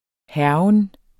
Udtale [ ˈhæɐ̯wən ]